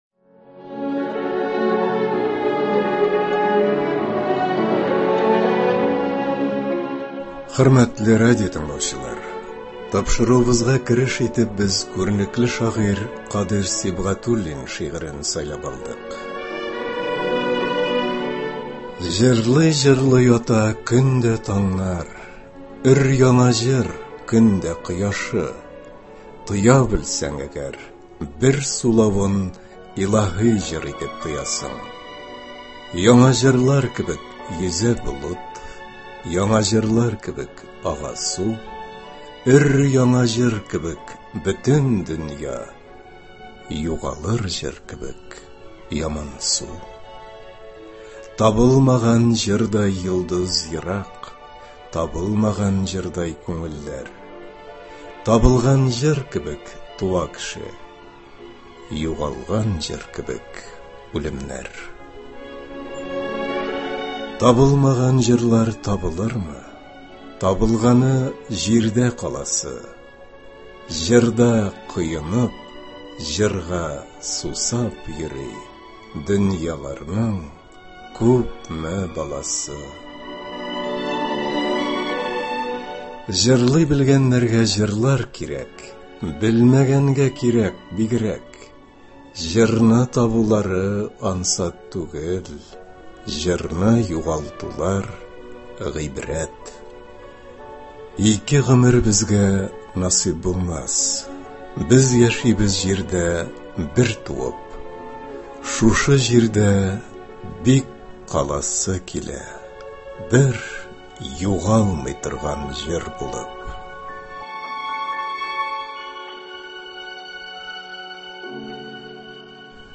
Г.Камал исемендәге Татар Дәүләт академия театрында узган иҗат кичәсеннән кайбер өлешләрне тәкъдим итәрбез.
Камал театрының яшь актерлары башкаруында шигъри батлл булган иде.